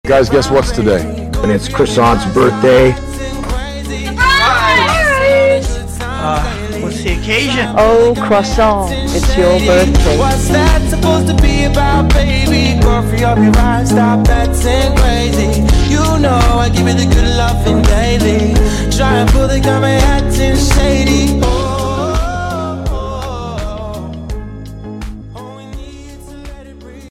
(slowed & reverb)